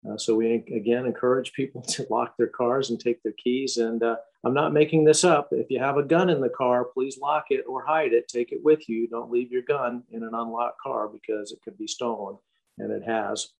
Butler shared his comments during Monday’s virtual intergovernmental luncheon, hosted by Riley County.